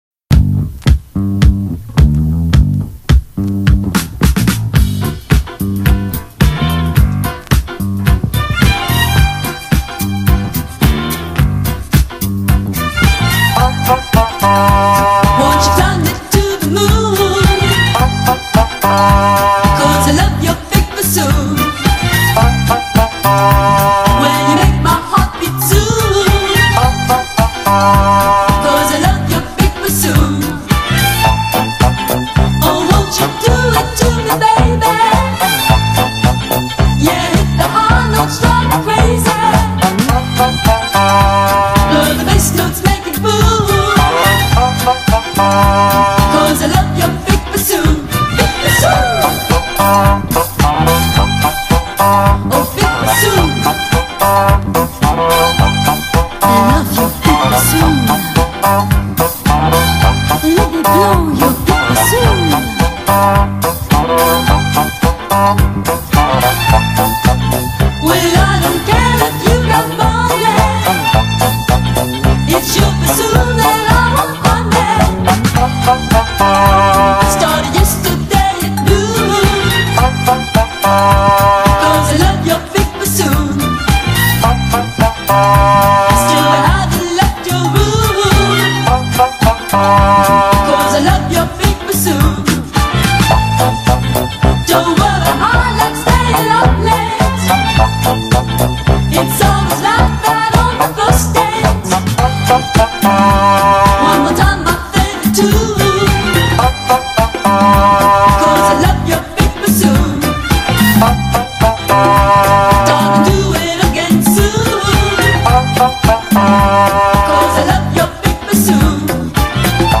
Жанр: Disco